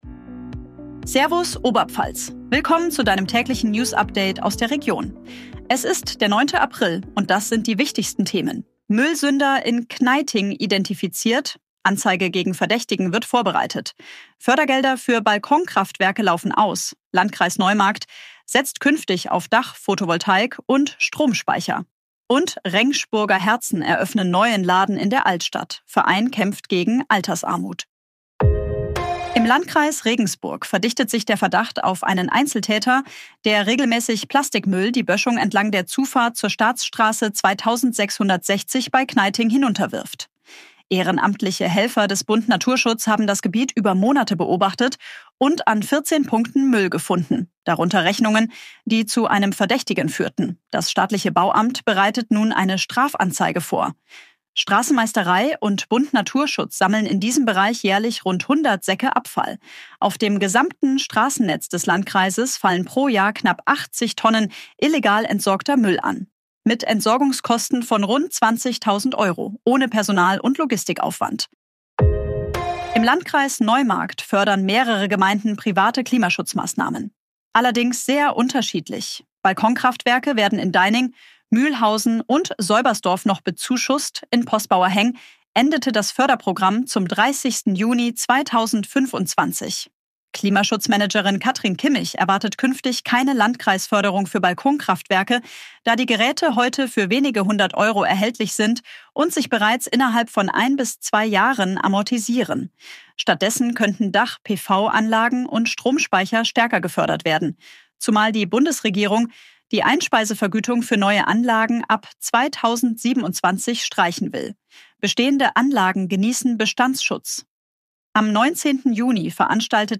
Tägliche Nachrichten aus deiner Region
Dein tägliches News-Update